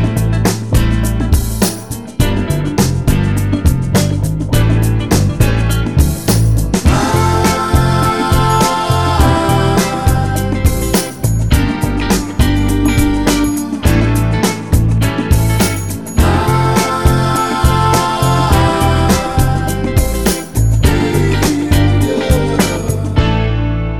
Two Semitones Down Pop (1980s) 4:08 Buy £1.50